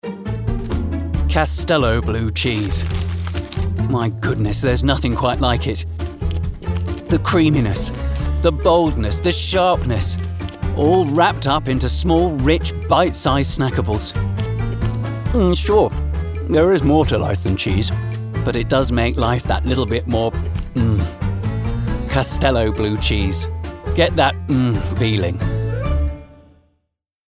Voice Reel
Castello Commercial - Cheerful, Animated, Quirky